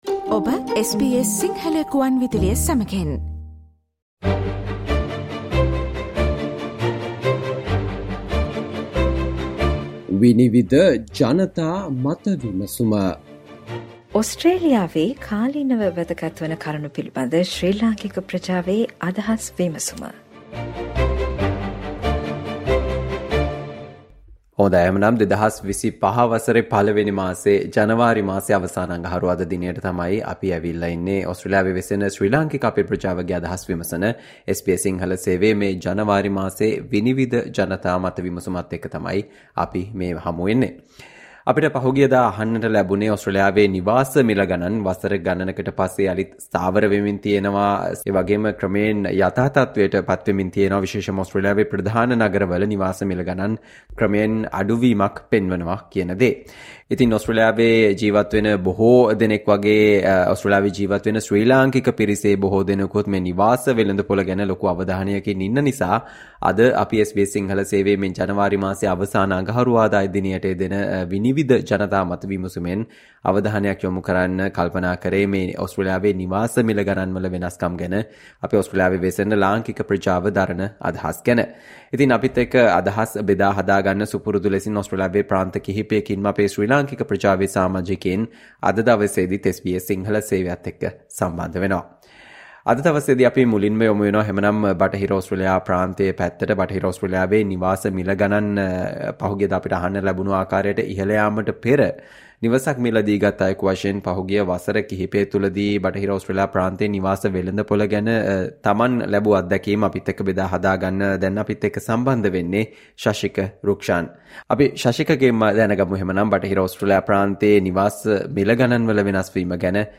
Listen to ideas from the members of Sri Lankan community in Australia talking about the changes in house prices in Australia.